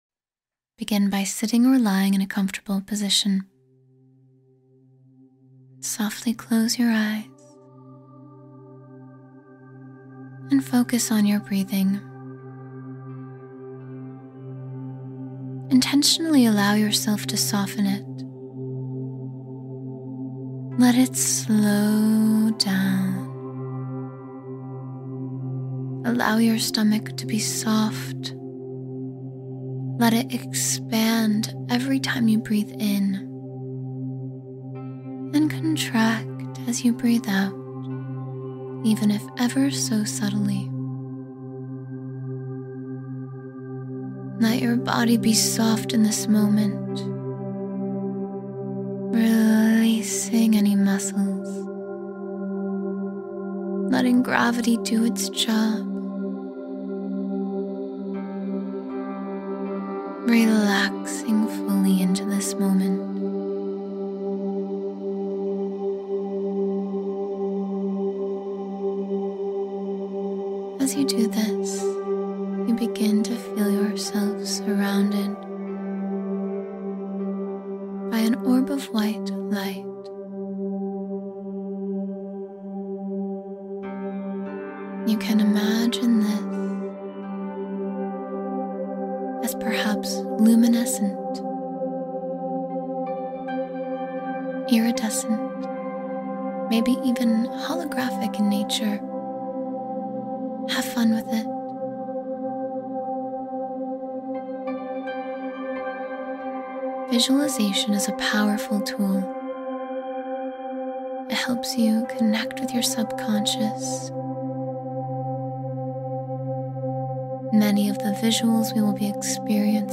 You Are Limitless — 10-Minute Meditation to Unlock Your Full Potential